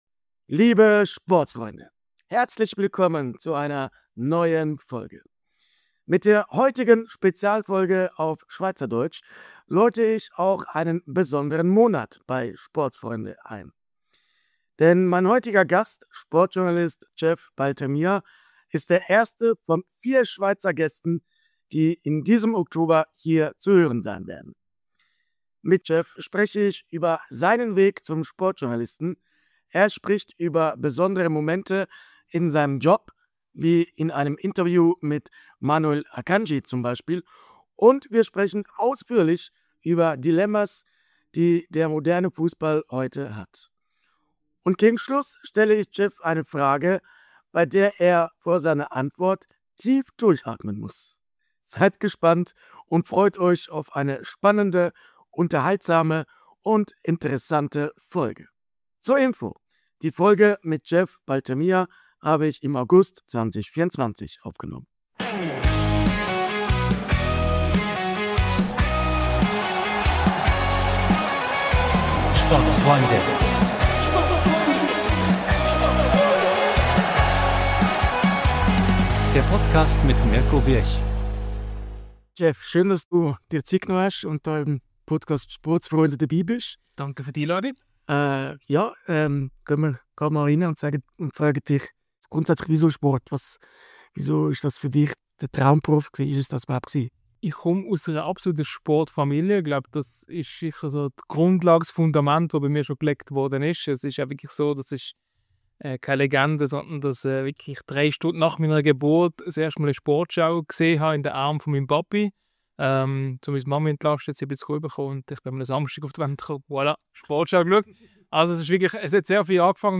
Beschreibung vor 6 Monaten Liebe Sportsfreunde, herzlich willkommen zu dieser Spezialfolge auf Schweizerdeutsch!